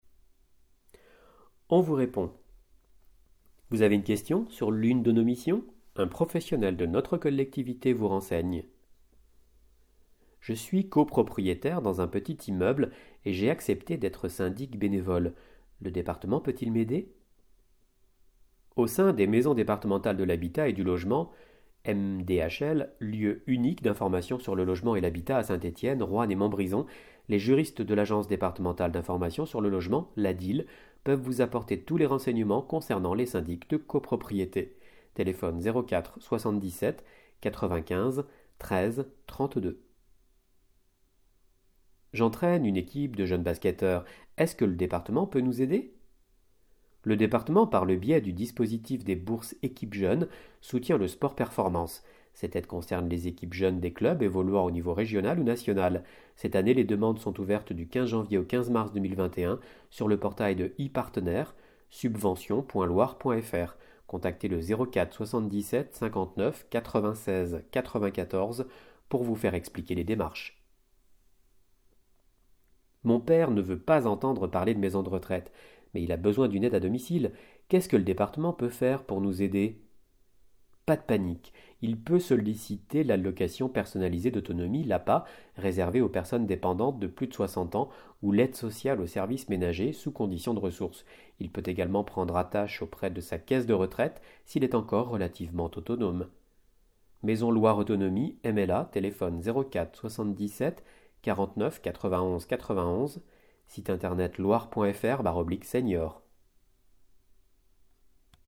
Loire Magazine n°145 version sonore